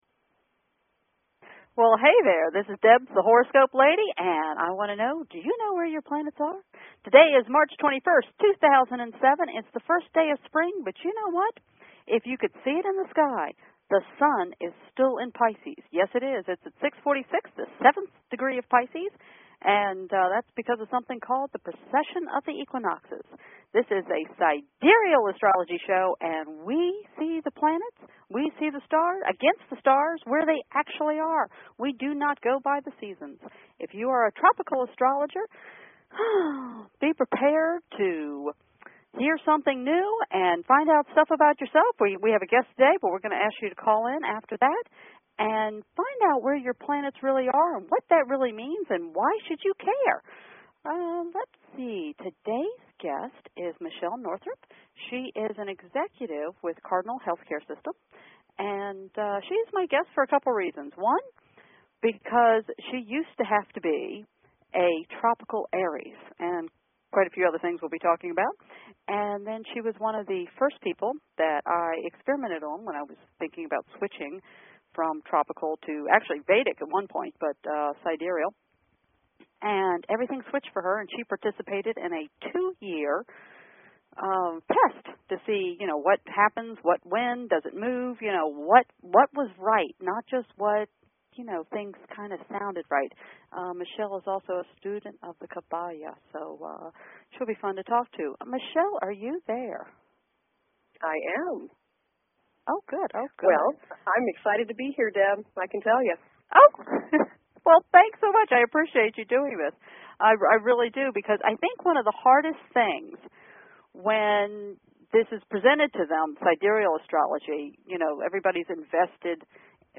Talk Show
Her guests include other leading sidereal astrologers and the occasional celebrity who has found sidereal astrology useful.